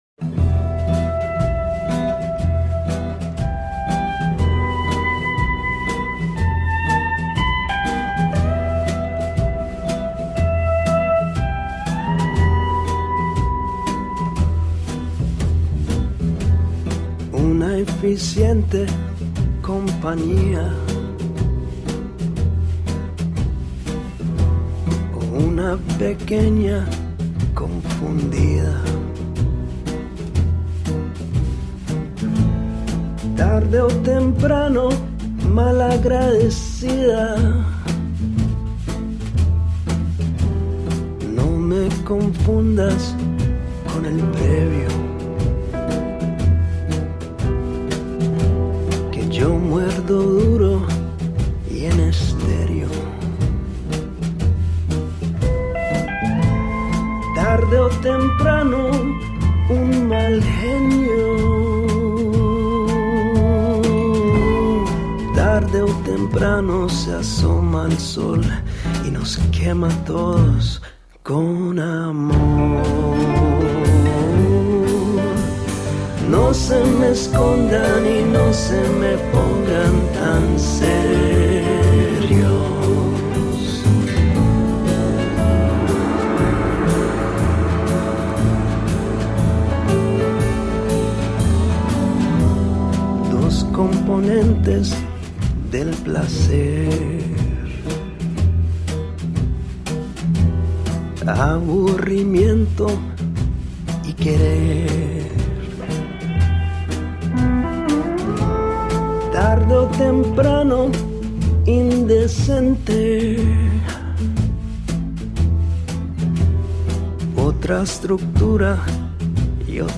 Catchy, whip smart alt-rock.
Upright bass, banjo, musical saw & lead guitar
Trumpet
Drums
Pedal steel
Trombone
Cello
Guitar, percussion, vocals & harmonium
(Indie-Americana en Español)